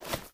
STEPS Dirt, Run 22.wav